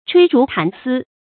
吹竹弹丝 chuí zhú dàn sī
吹竹弹丝发音